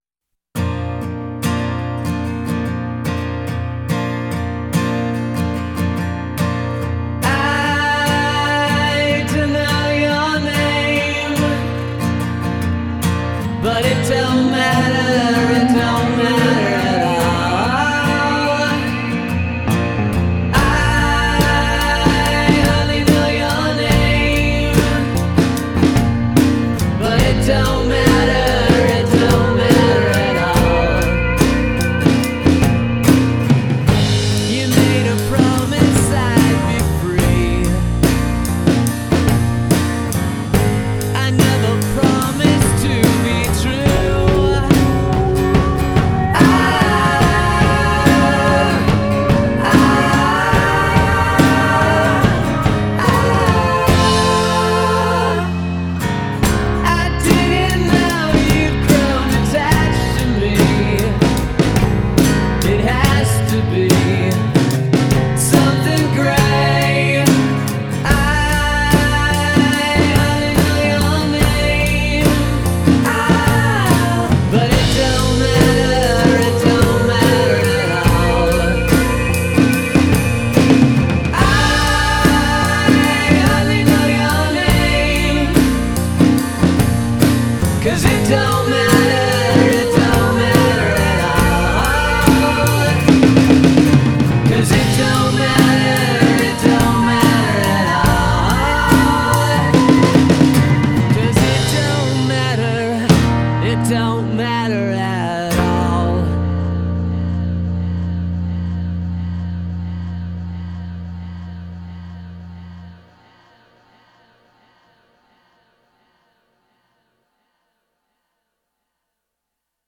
self-produced and home-recorded
classic mid-1960s British Invasion rock and roll sound